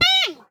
assets / minecraft / sounds / mob / panda / hurt5.ogg
hurt5.ogg